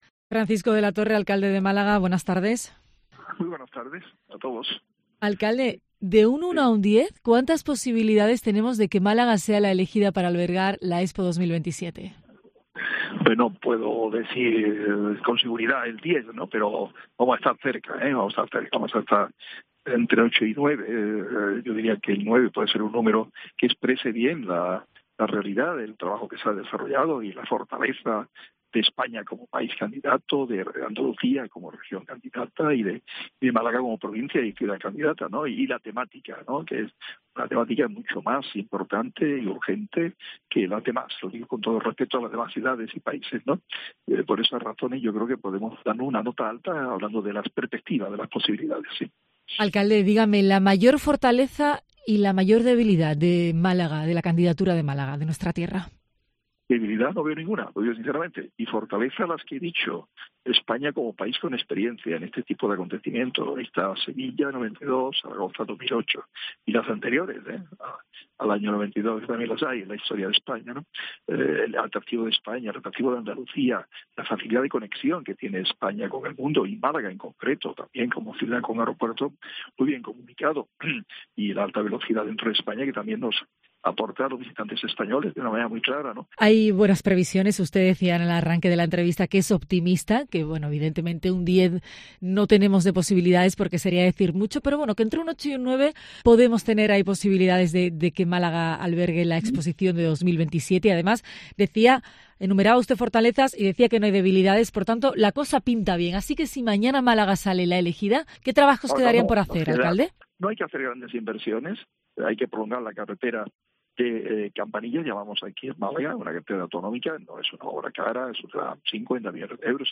Hablamos con Francisco de la Torre cuando va camino de París.
En medio de todo esto, el alcalde de Málaga atiende a COPE Andalucía.